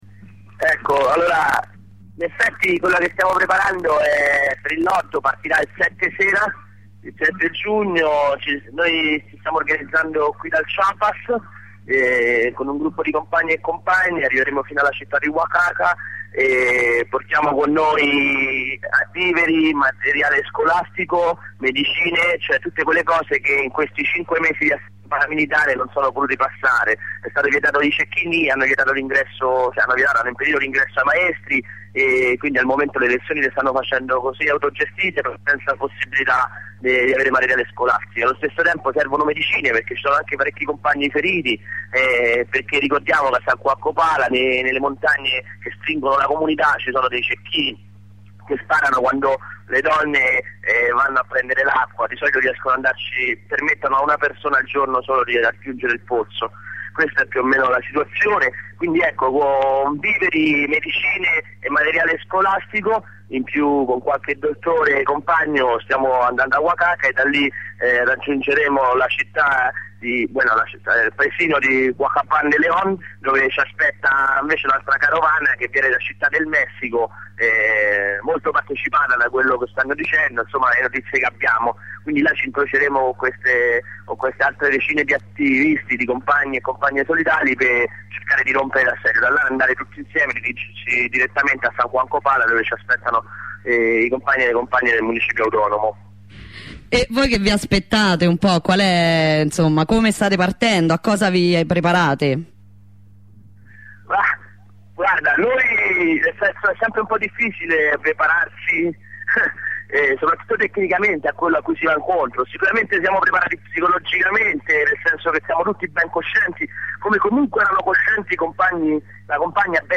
Abbiamo parlato con un compagno italiano che parteciperà alla carovana.